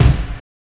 SPILL.wav